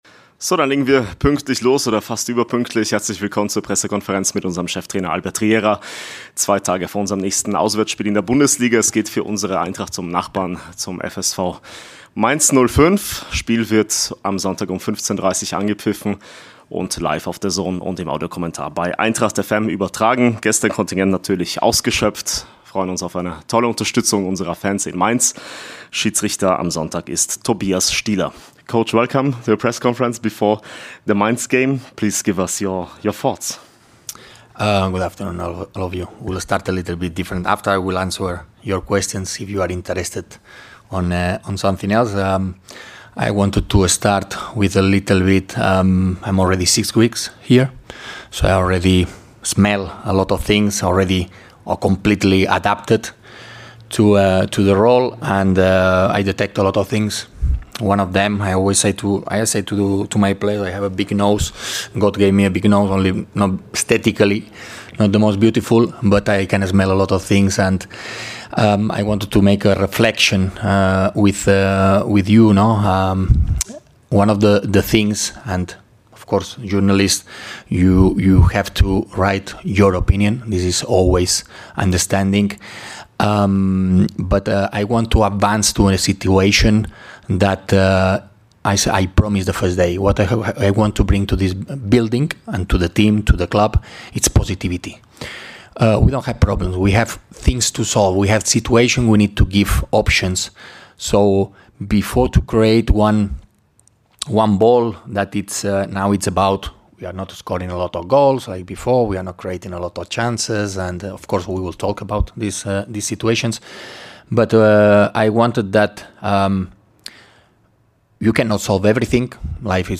Die Pressekonferenz vor dem Bundesliga-Auswärtsspiel beim Tabellendreizehnten mit Cheftrainer Albert Riera.